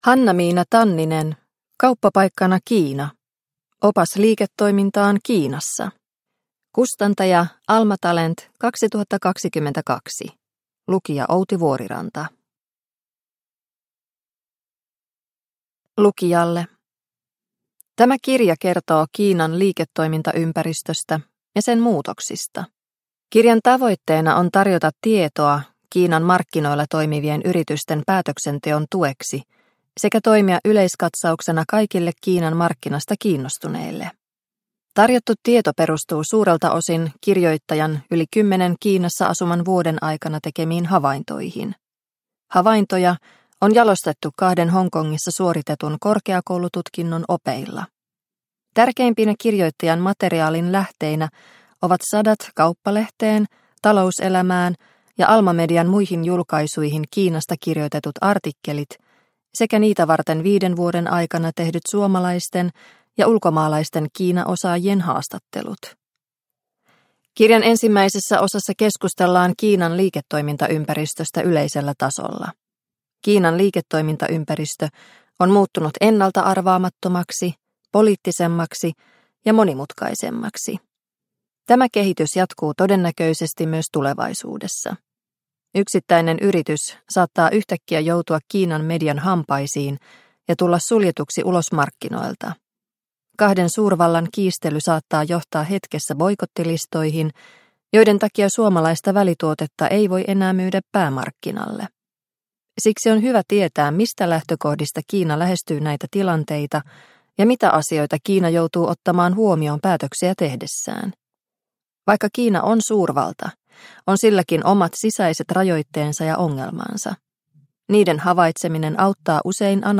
Kauppapaikkana Kiina – Ljudbok – Laddas ner